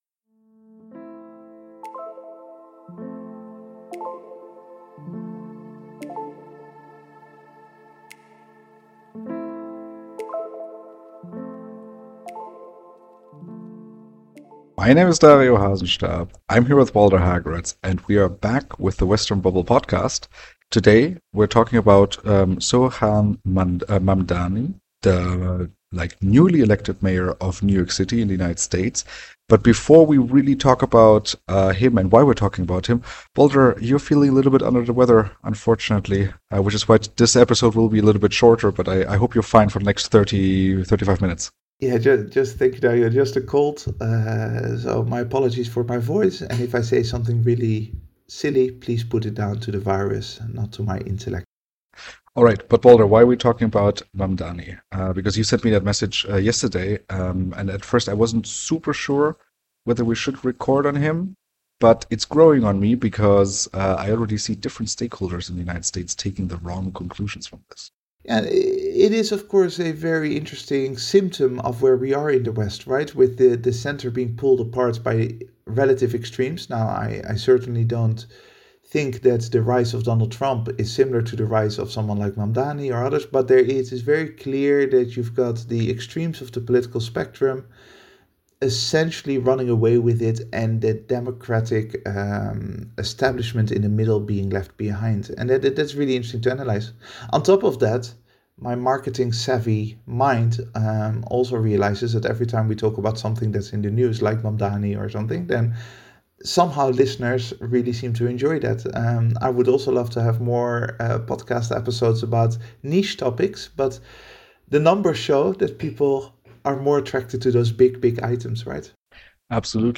1 This Week in Global Dev: #120: After 2025’s ‘seismic’ shock, what’s next for development and aid? 40:17 Play Pause 10h ago 40:17 Play Pause Play later Play later Lists Like Liked 40:17 On the sidelines of the World Economic Forum’s Humanitarian and Resilience Investing Initiative’s Frontier Markets Impact Meeting in Geneva, Devex teamed up with Radio Davos for a special podcast episode.